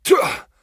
pain_12.ogg